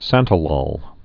(săntə-lôl, -lōl, -lŏl)